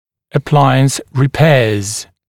[ə’plaɪəns rɪ’peəz][э’плайэнс ри’пэаз]починка аппарата